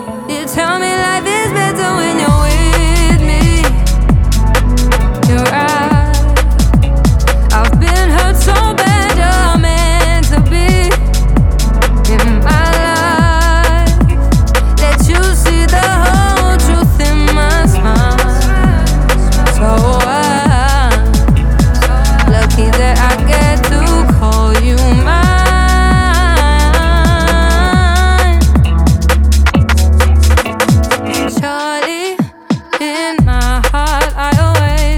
Electronic
2025-07-25 Жанр: Электроника Длительность